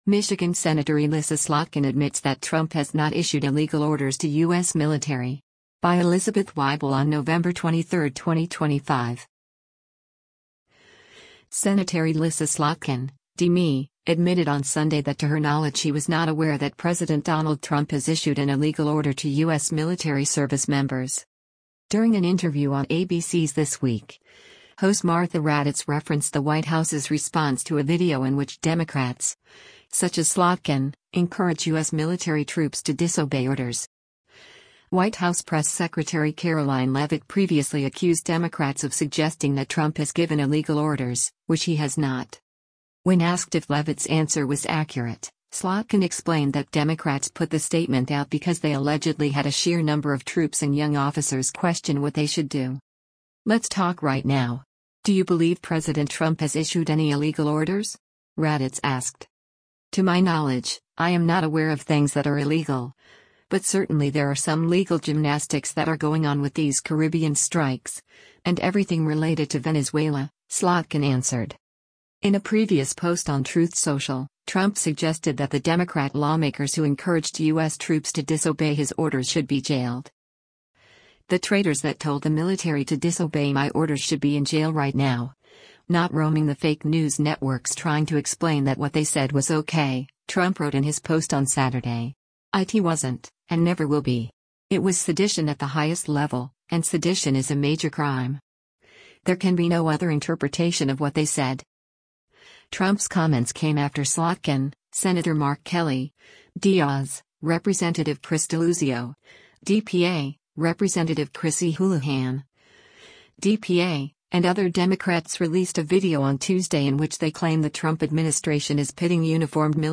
During an interview on ABC’s This Week, host Martha Raddatz referenced the White House’s response to a video in which Democrats, such as Slotkin, encourage U.S. military troops to disobey orders.